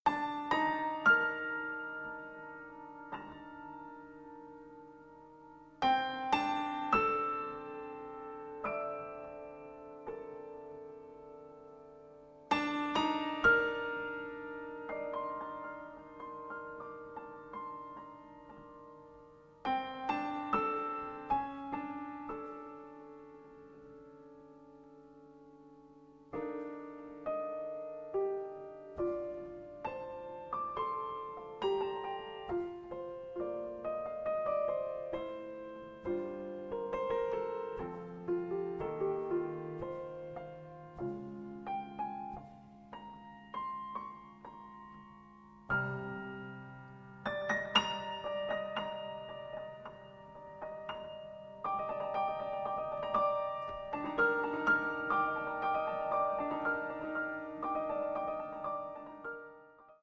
batteria
pianoforte
contrabbasso